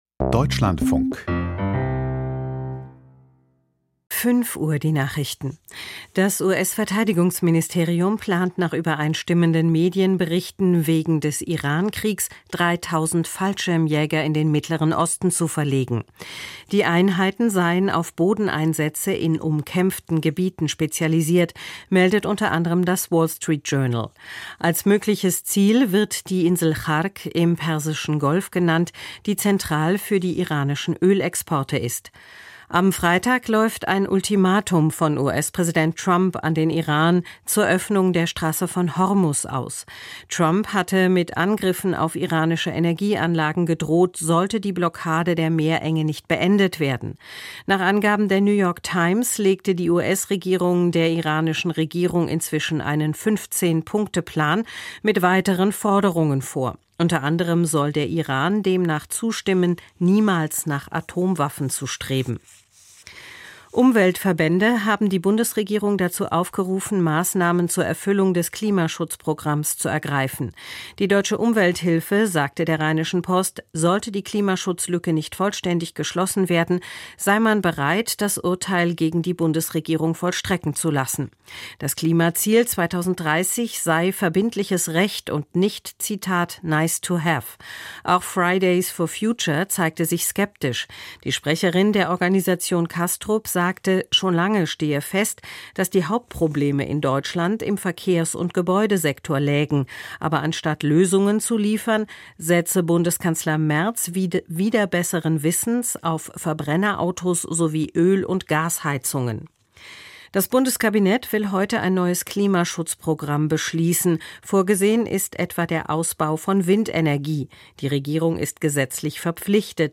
Die Nachrichten vom 25.03.2026, 05:00 Uhr